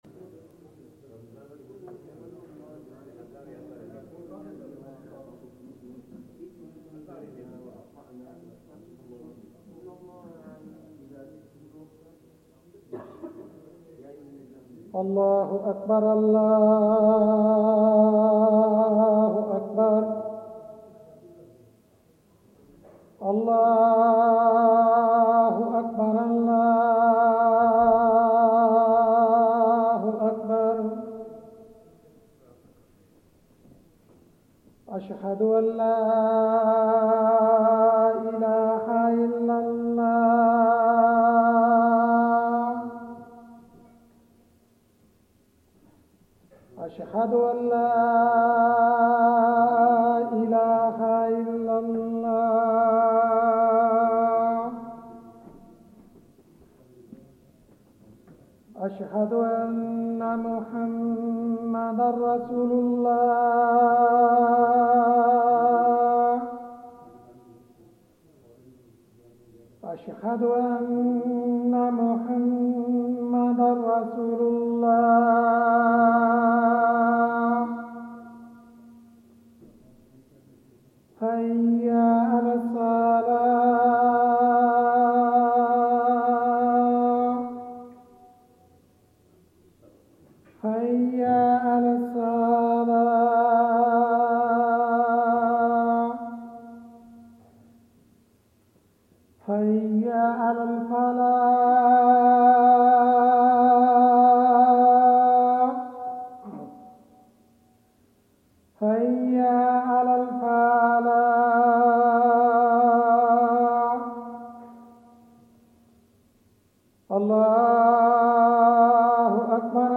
Taraweeh 25th Ramadhan